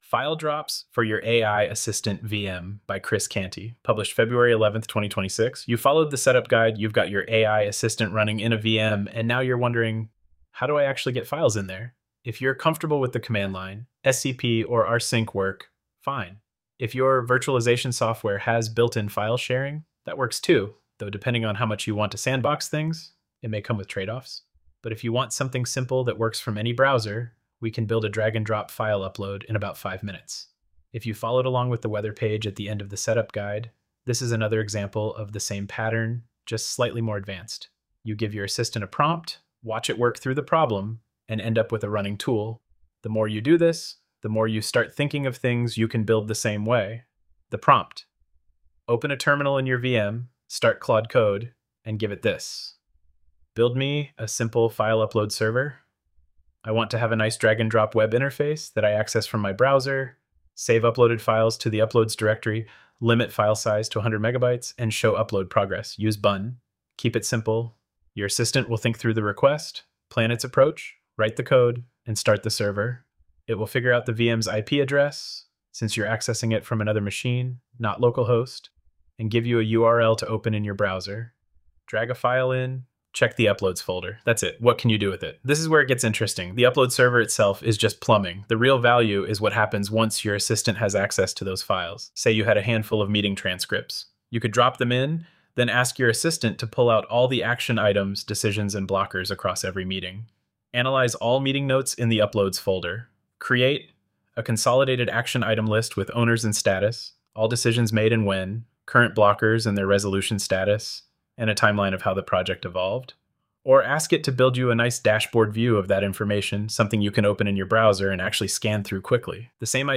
AI-generated narration